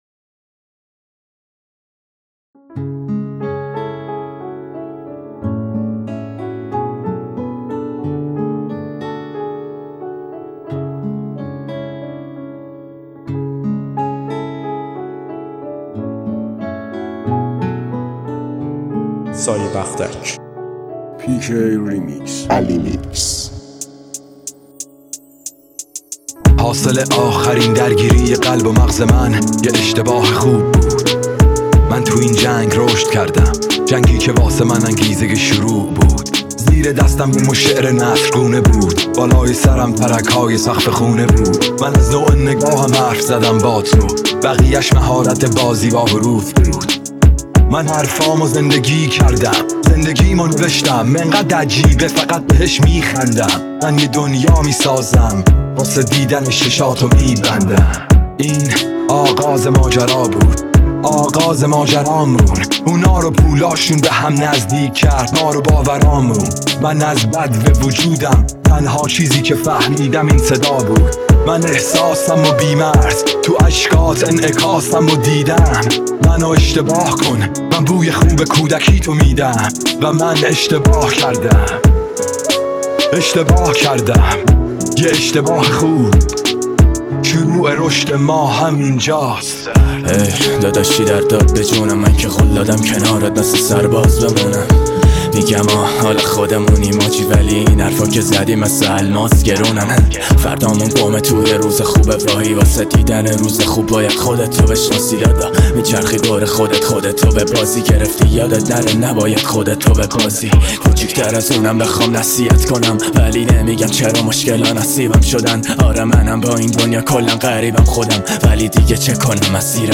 ریمیکس غمگین رپ